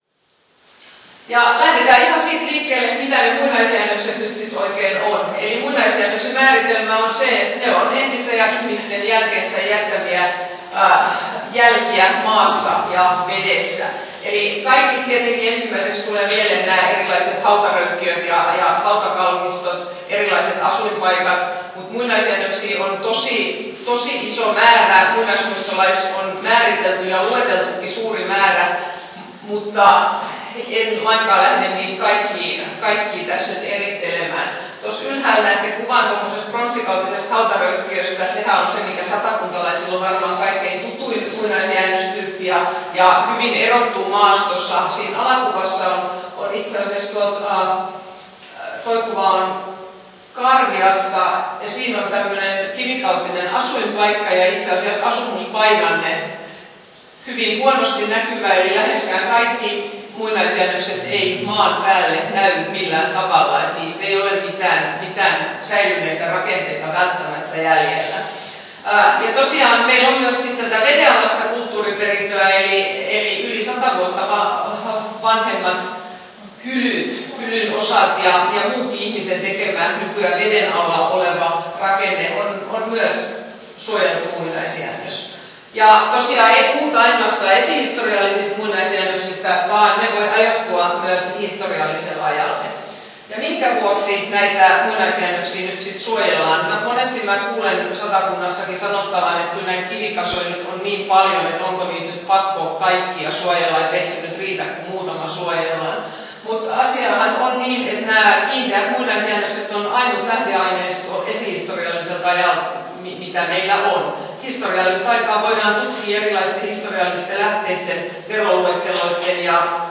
Luento 44 min.